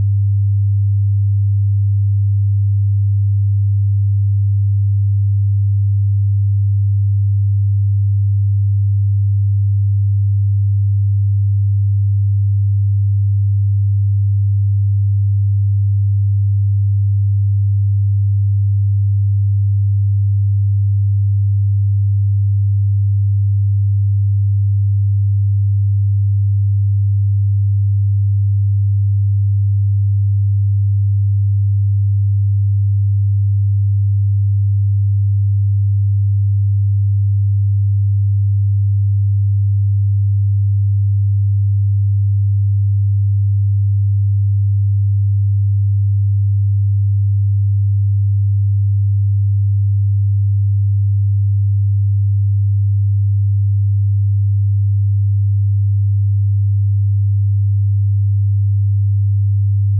Secondo i ricercatori, ascoltare un suono continuo a 100 Hz per circa un minuto può ridurre sensibilmente la nausea da movimento.
🎧Ascolta  il file audio a 100 Hz
tono_100Hz_60sec.wav